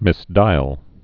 (mĭs-dīəl, -dīl)